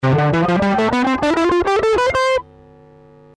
The Major Scale
Listen (Fast Speed)
CMajorScale2.mp3